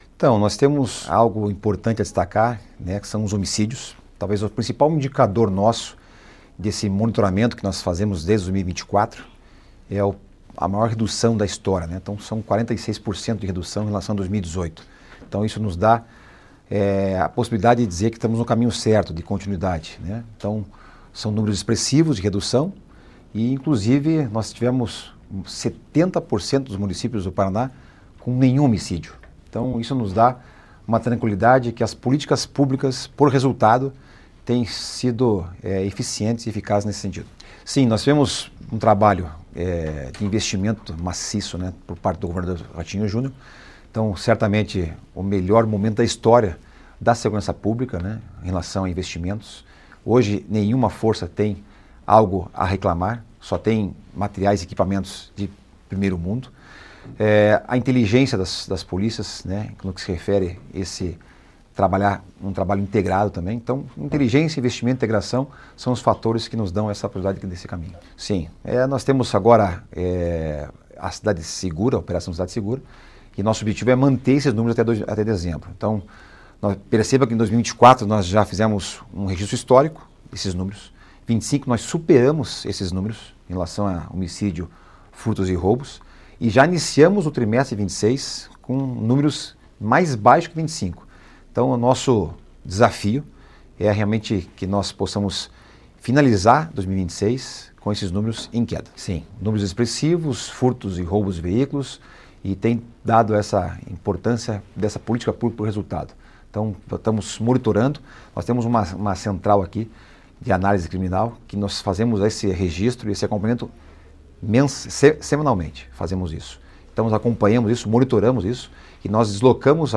Sonora do secretário da Segurança Pública, Saulo Sanson, sobre a redução dos índices de criminalidade no Paraná 1º trimestre de 2026